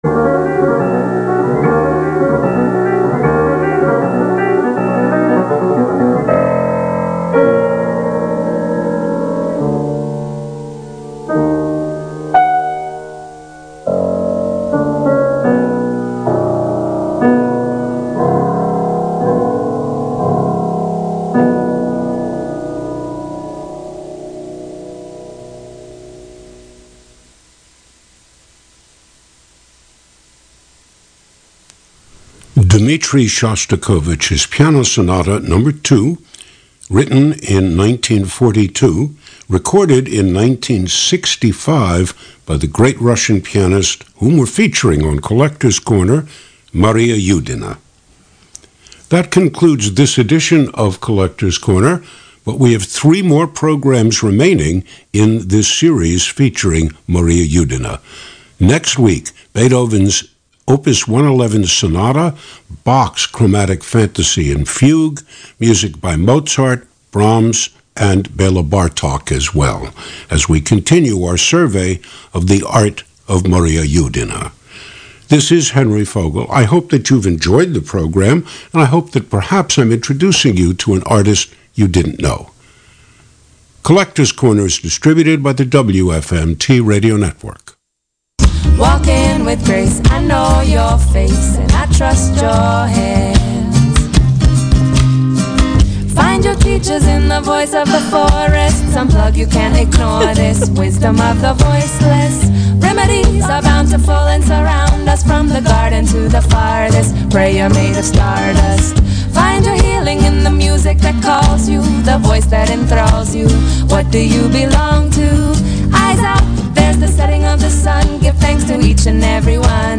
– A Radio Interview on Holistic Health Perspectives